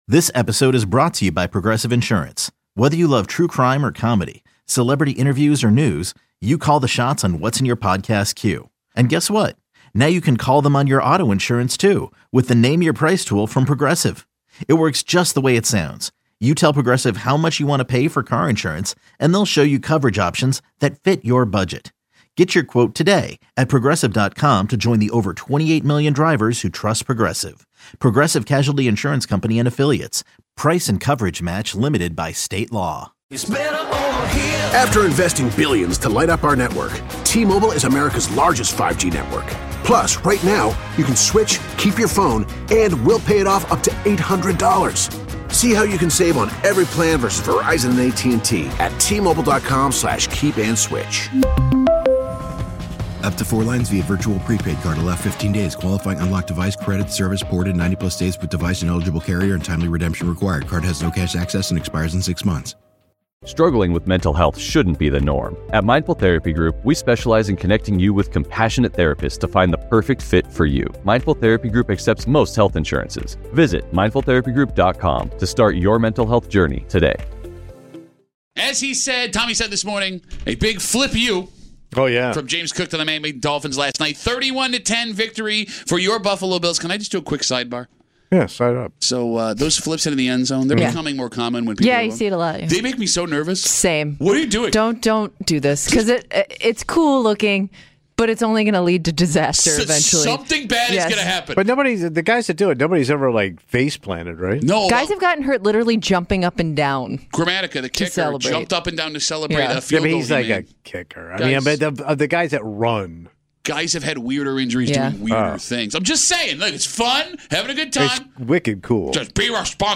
joins the Break Room LIVE from the TSA line at the Miami airport to break down Buffalo's win over the Dolphins.